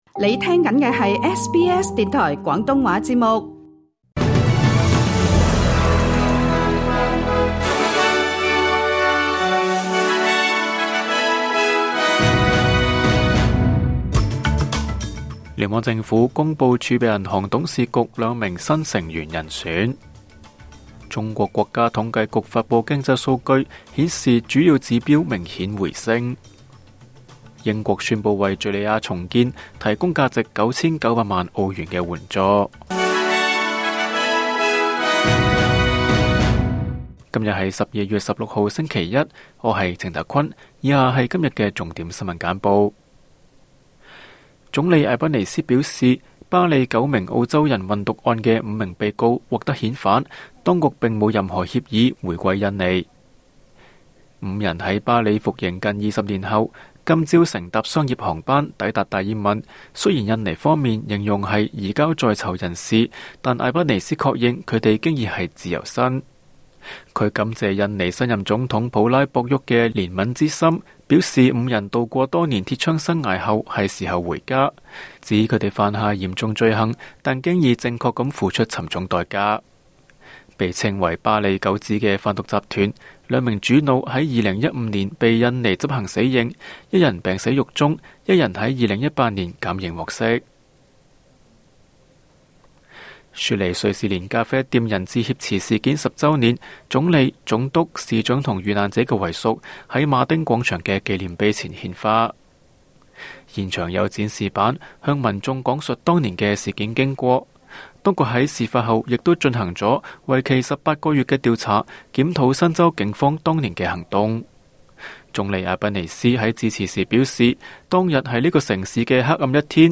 SBS 晚間新聞（2024年12月16日）
請收聽本台為大家準備的每日重點新聞簡報。